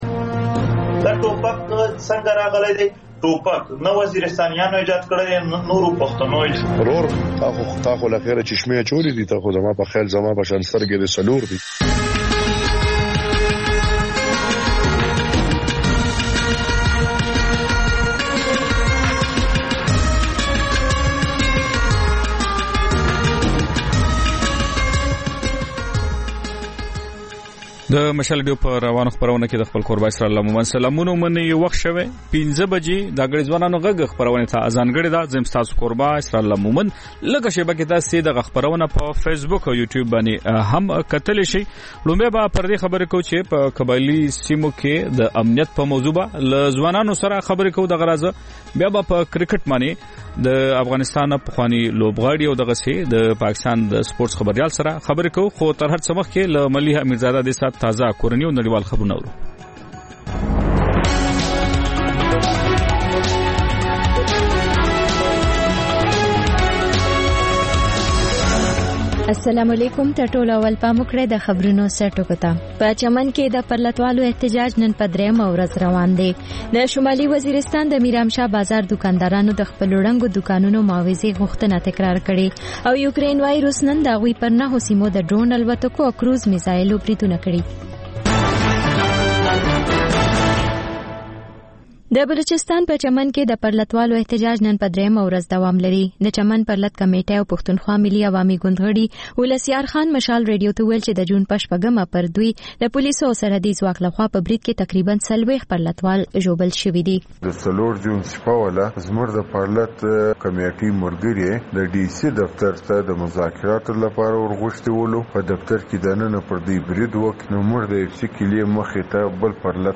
د مشال راډیو ماښامنۍ خپرونه. د خپرونې پیل له خبرونو کېږي، بیا ورپسې رپورټونه خپرېږي.
ځېنې ورځې دا ماښامنۍ خپرونه مو یوې ژوندۍ اوونیزې خپرونې ته ځانګړې کړې وي چې تر خبرونو سمدستي وروسته خپرېږي.